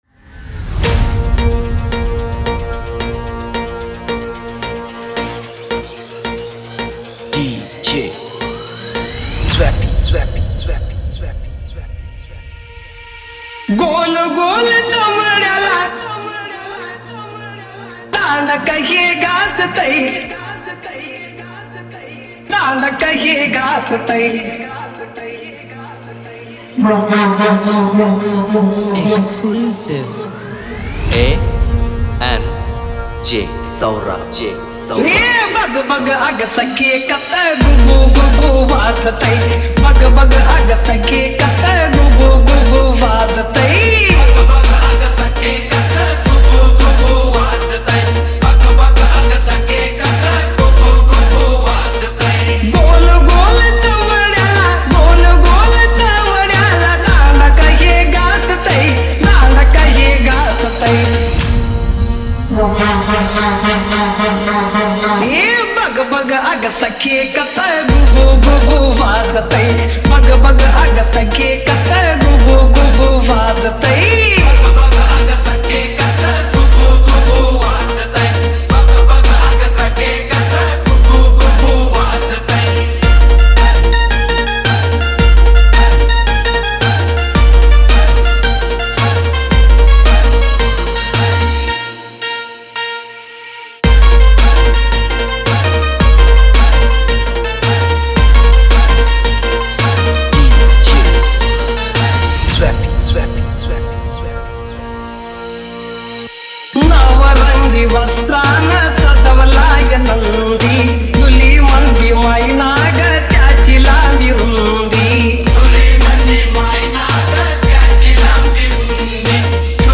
Marathi Dj Single 2025
Marathi Sound Check 2025